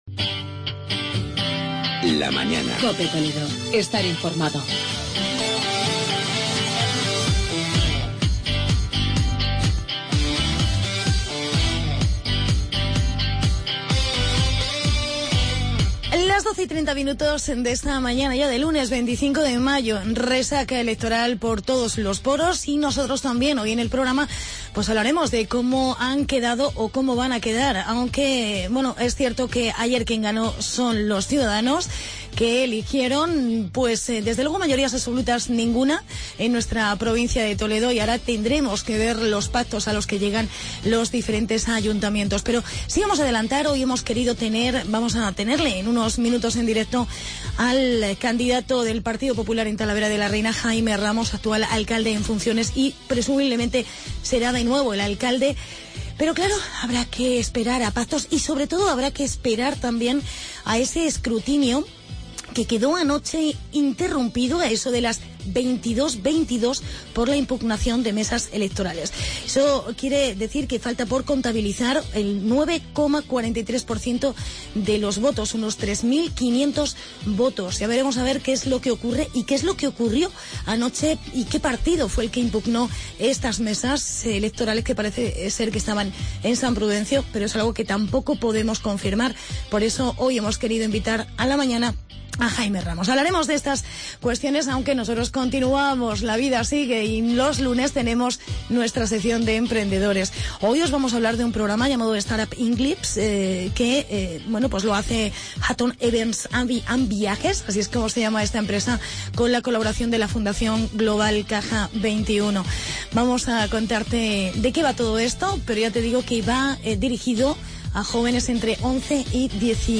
Entrevistamos al alcalde en funciones, Jaime Ramos